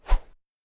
sfx_woosh_3.mp3